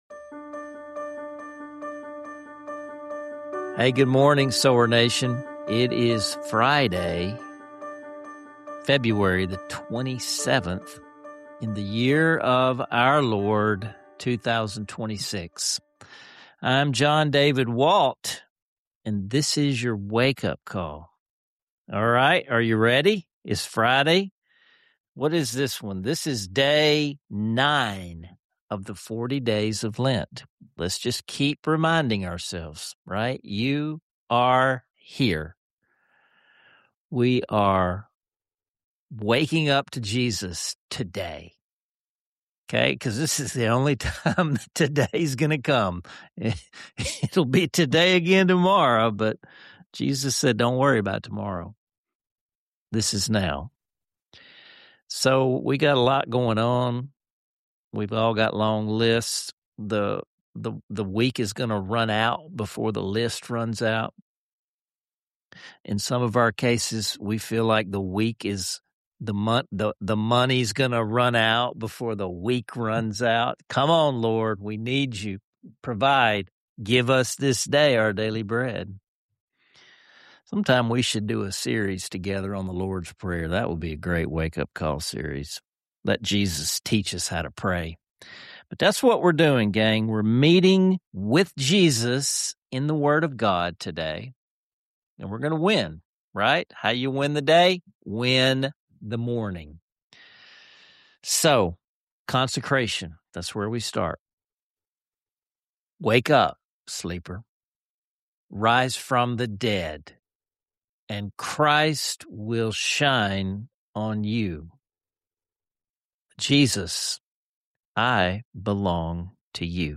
Plus, the father-son duo leads a heartfelt, multi-generational hymn sing-along, reminding us of the timeless value of faith, worship, and being led by the Savior Himself.